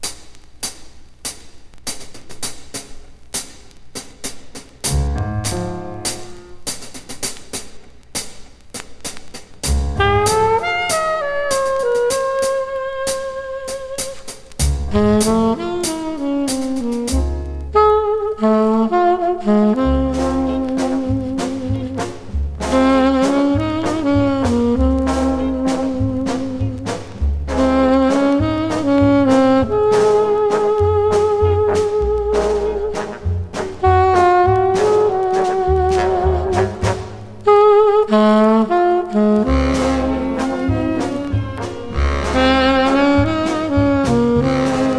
躍動感溢れる演奏、艶やかでふくよかなトーンと聴きどころ満載の良盤です。
Jazz　USA　12inchレコード　33rpm　Mono